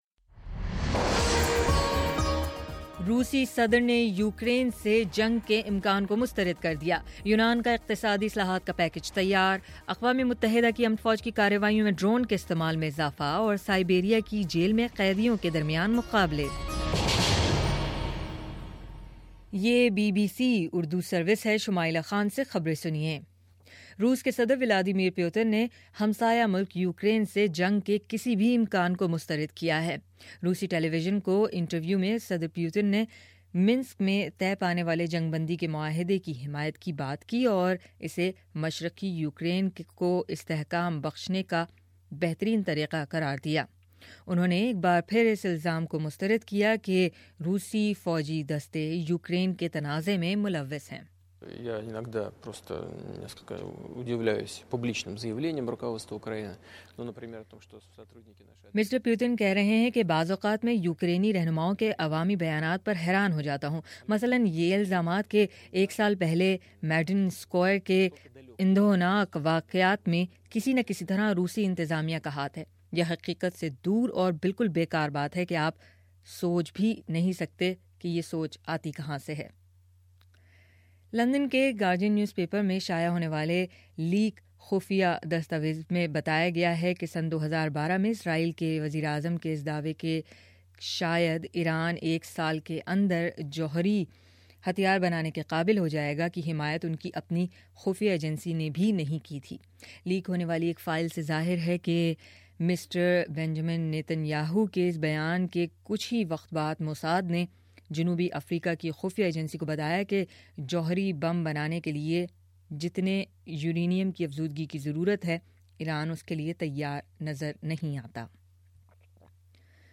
فروری 24 : صبح نو بجے کا نیوز بُلیٹن